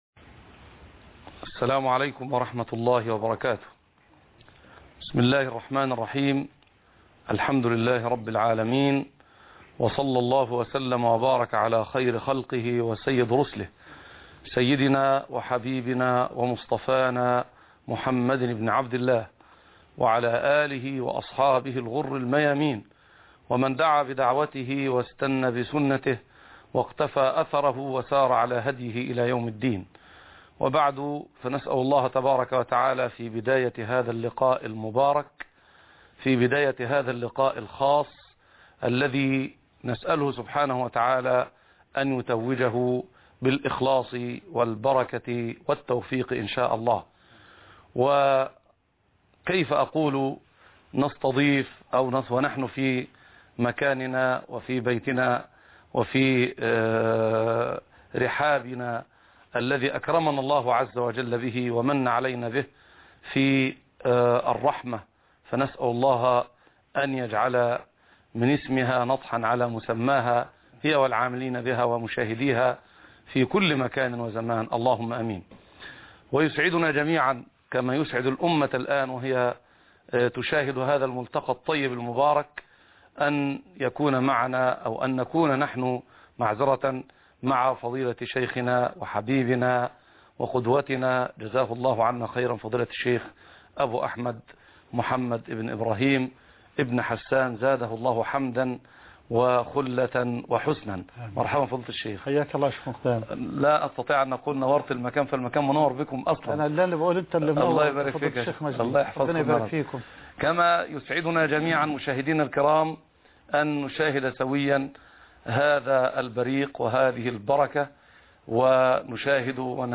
فن الدعوة (31/10/2009) لقاء خاص - فضيلة الشيخ محمد حسان